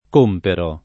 comprare v.; compro [k1mpro] — anche comperare: compero [